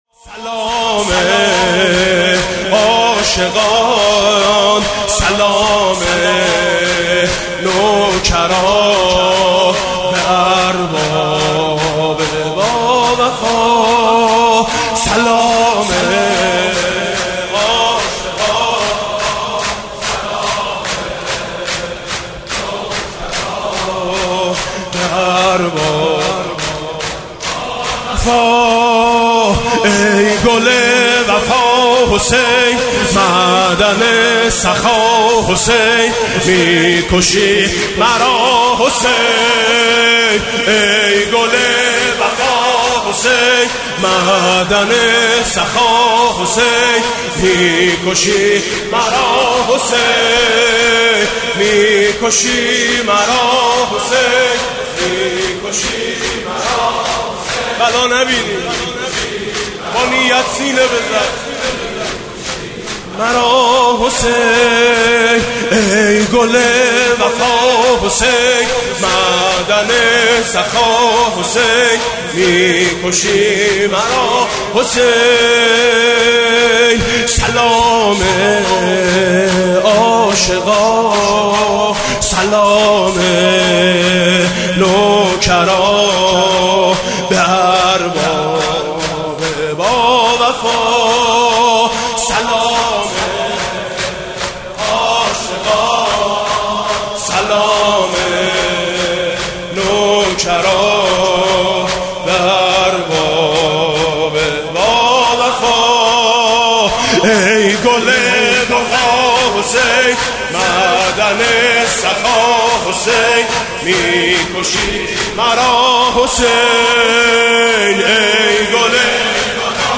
نوا